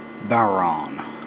That's pronounced
bow-rawn (as in when you take a bow).
bodhran.wav